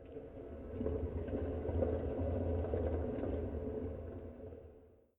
scpcb-godot/SFX/Ambient/Zone2/Ambient7.ogg at d1278b1e4f0e2b319130f81458b470fe56e70c55
Ambient7.ogg